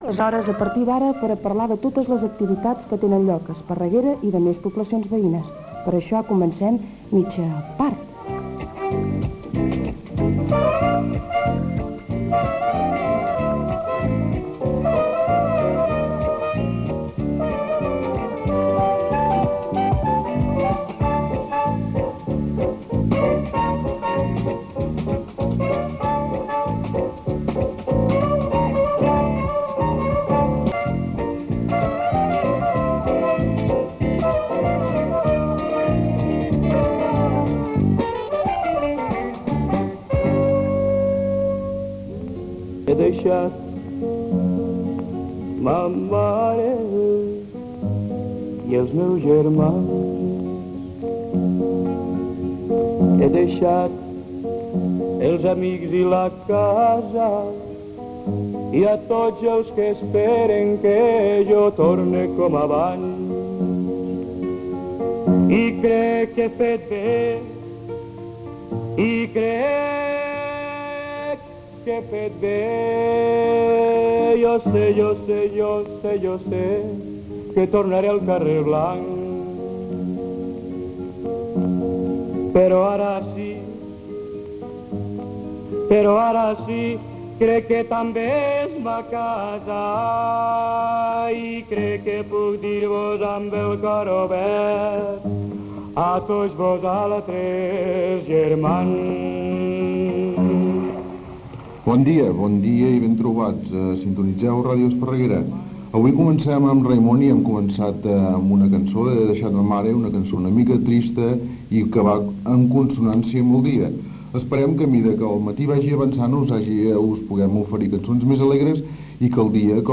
Careta amb sintonia, tema musical, presentació, sumari, equip, tema musical, farmàcies de guàrdia, rifes, estat del temps, taxi de guàrdia, estat de la mar
Entreteniment